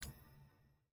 sfx-exalted-hub-summon-x10-hover.ogg